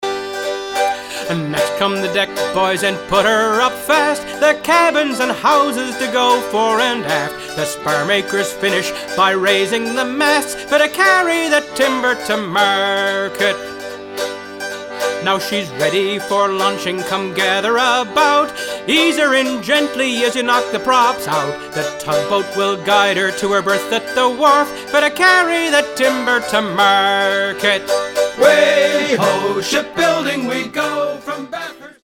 - sea song style sing-a-long